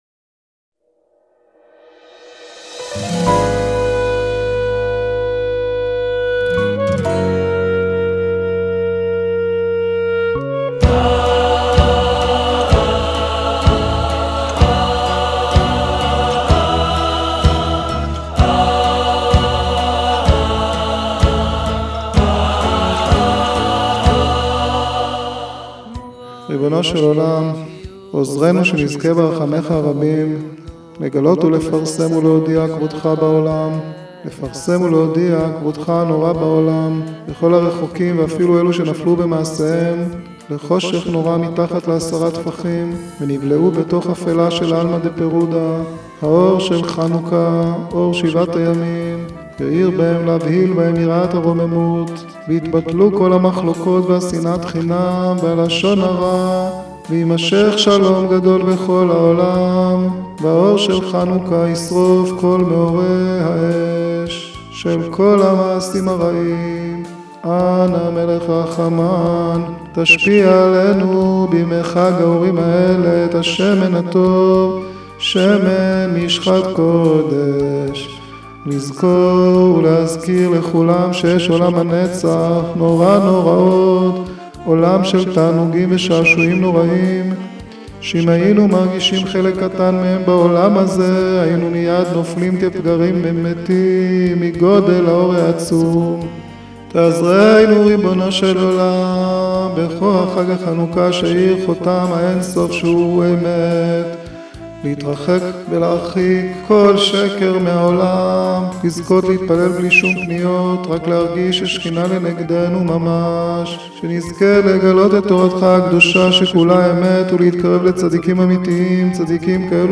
שיעור מיוחד לגולשי האתר